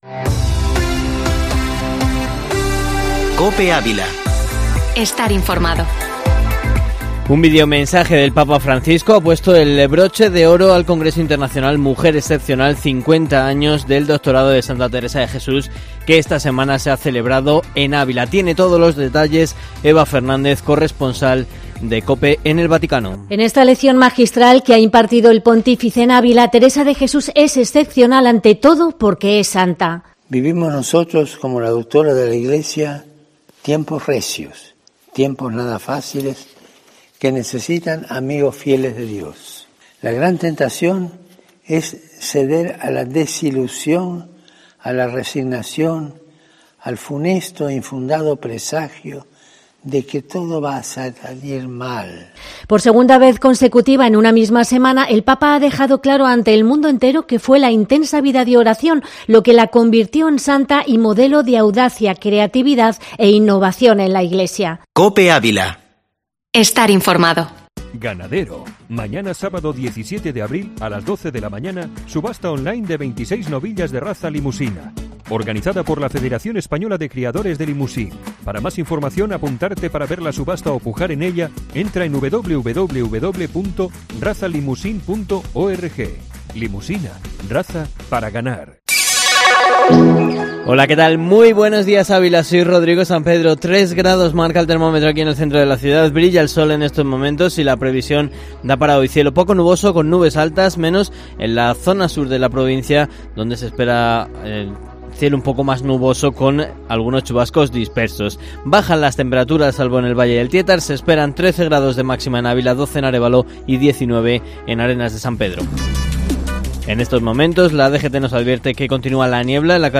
Informativo matinal Herrera en COPE Ávila 16/04/2021